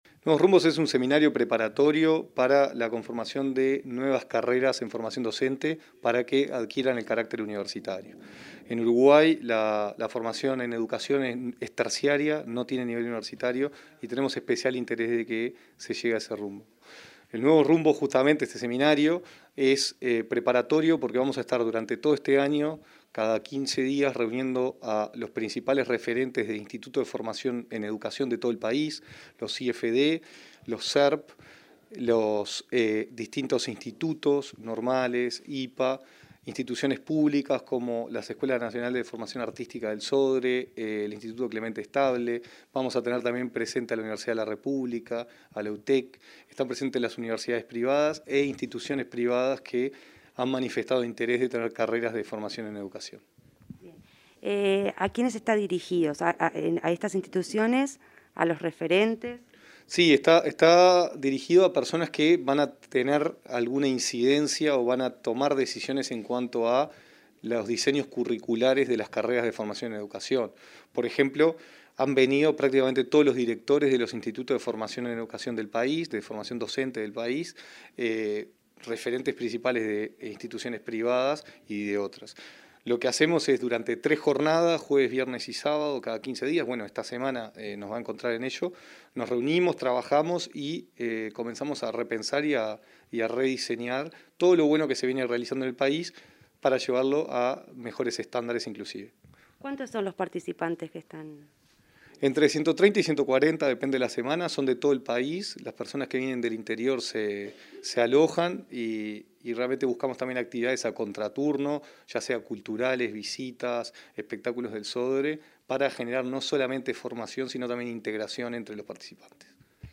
Entrevista al director de Educación, Gonzalo Baroni